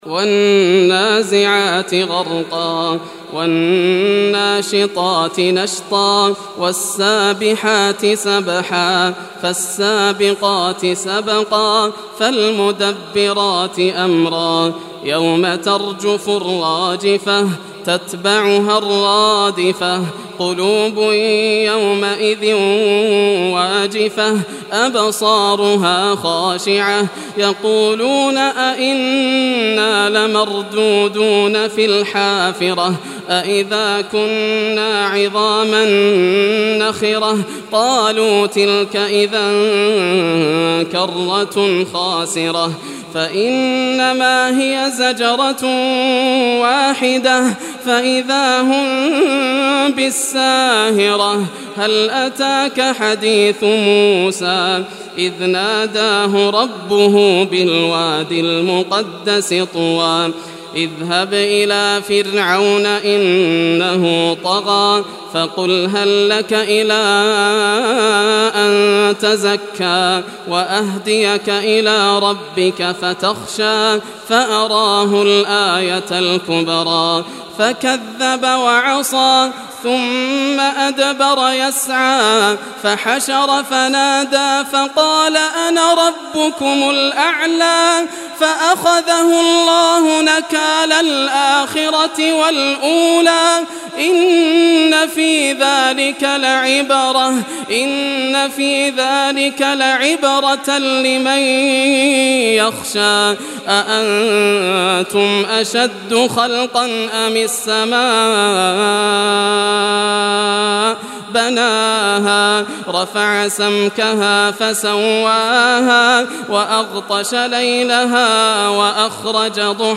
Surah Naziat, listen or play online mp3 tilawat / recitation in Arabic in the beautiful voice of Sheikh Yasser al Dosari.